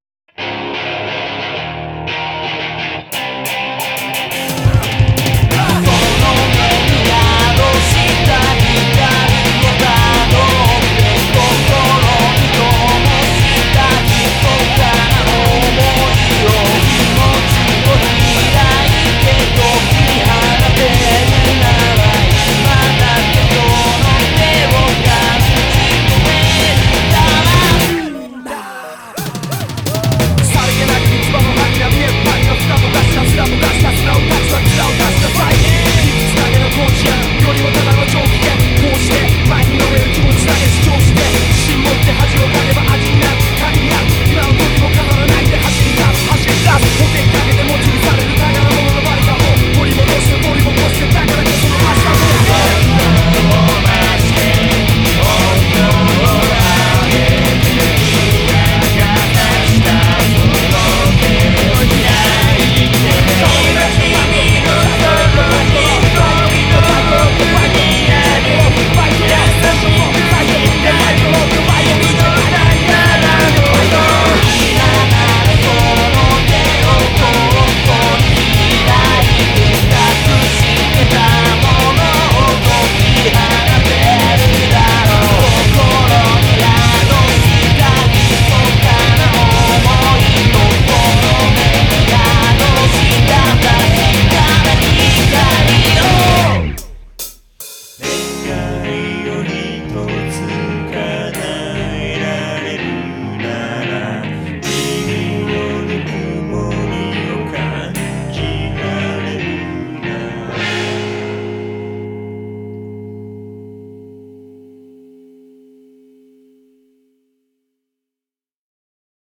BPM145-175
Audio QualityPerfect (Low Quality)